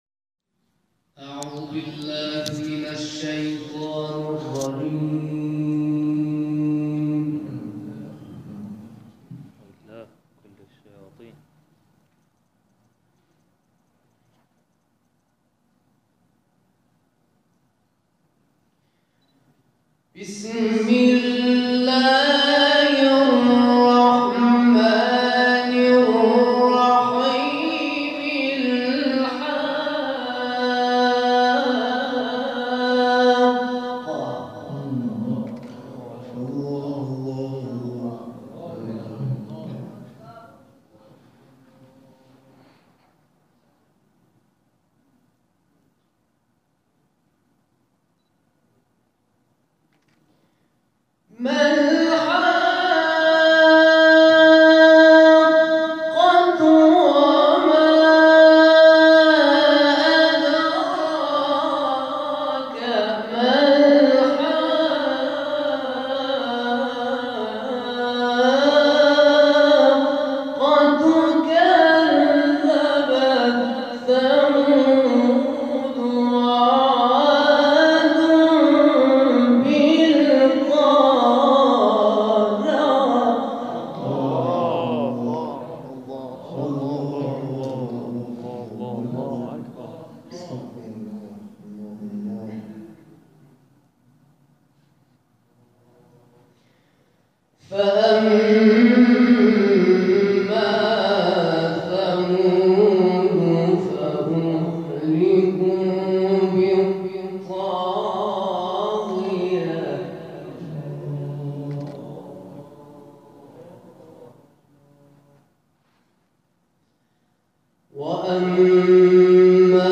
آیات ابتدایی سوره الحاقه را به سبک استاد «غلوش» تلاوت کرد که مورد تشویق حاضرین در جلسه قرار گرفت.
در ادامه تلاوت‌های این مراسم ارائه می‌شود.
تلاوت